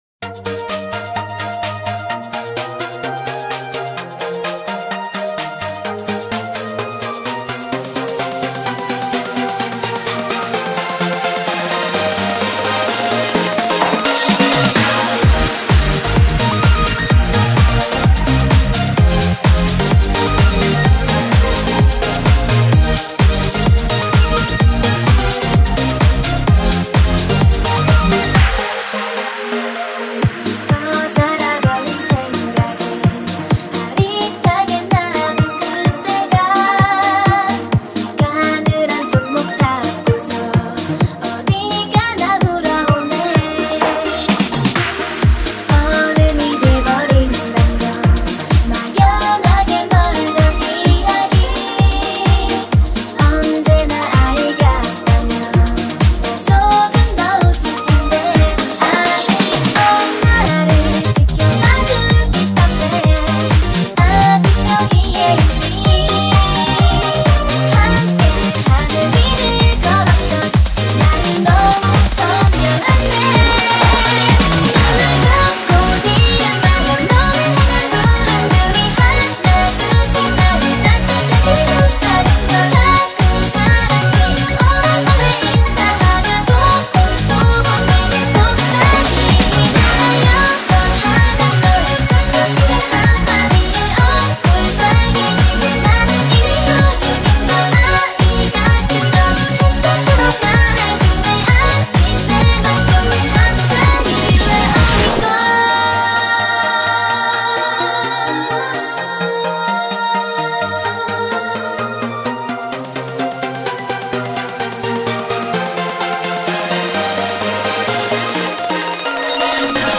BPM128
Audio QualityCut From Video